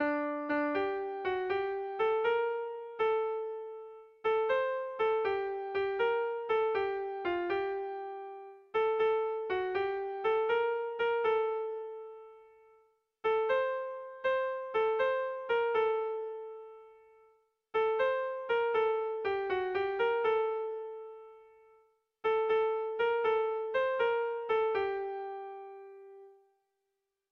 Irrizkoa
ABDE